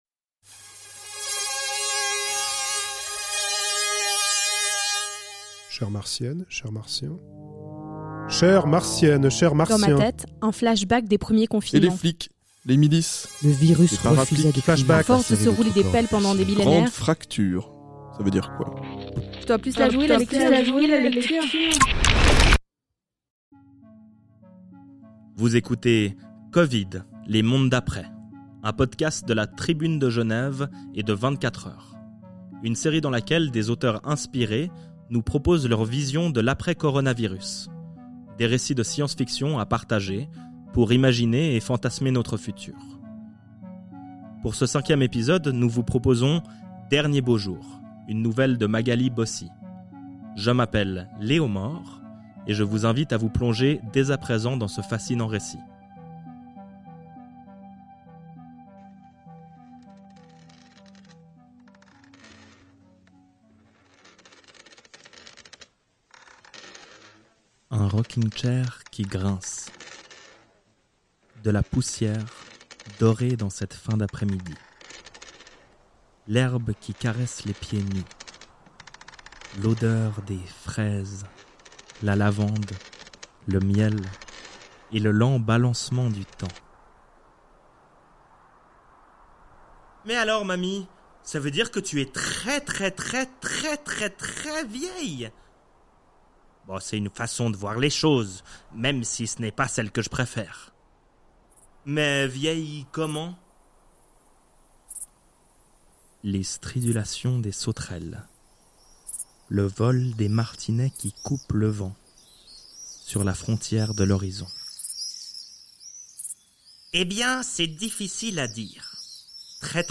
Genres: Fiction, Science Fiction
Voitures à essence, pangolins ou encore baleines à bosse, ensemble elles reviennent sur toutes ces étrangetés d'un lointain passé. Un récit poétique lu et raconté par le comédien